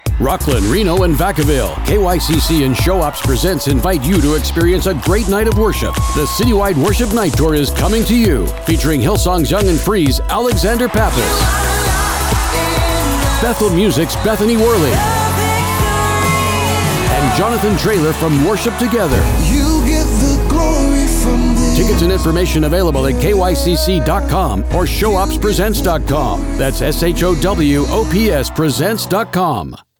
Inspire and uplift your audience with a sincere, heartfelt voice that aligns with the message of contemporary Christian music.
Concert Promos
Contemporary Christian II
ShowOps_CityWide_applause_mixdown.mp3